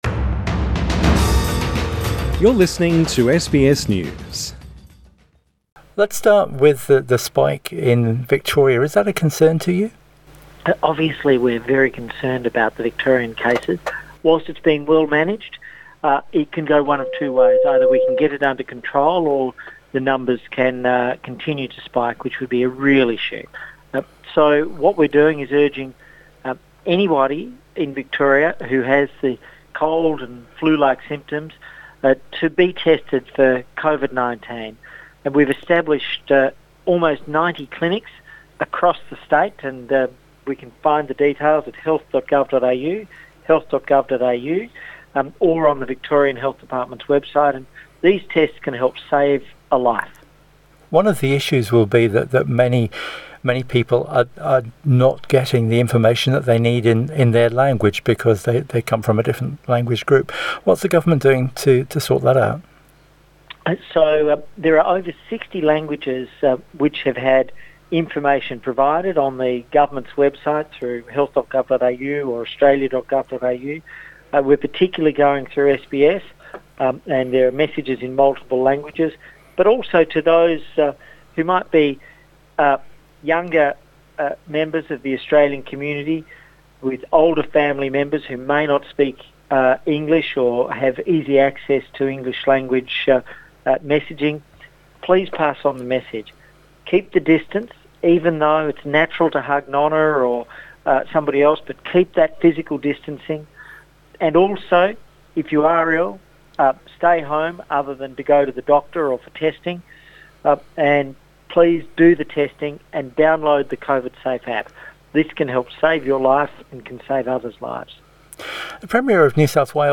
Health Minister Greg Hunt speaks to SBS